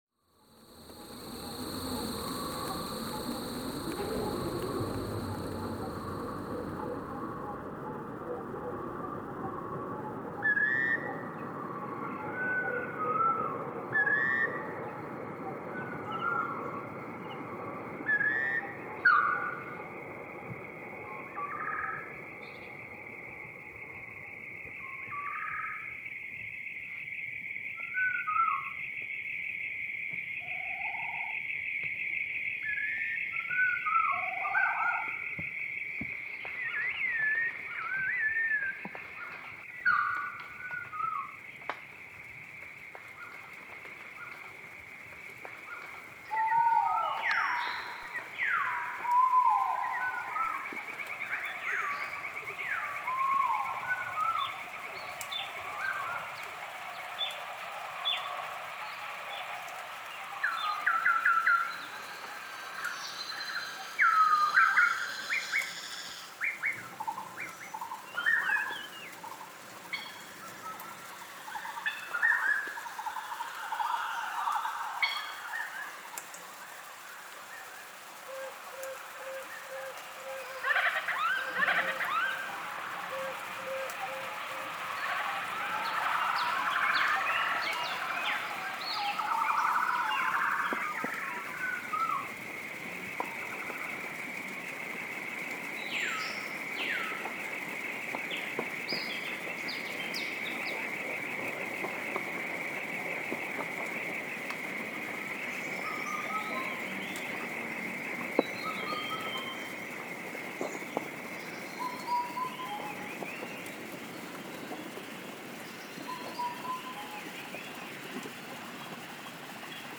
Une joute sonore
de ses sons naturels, l’un nourrit l’autre, qui, à son tour, nourrit l’un avec les sons transformés et ainsi de suite - un ensemble authentiquement surréaliste.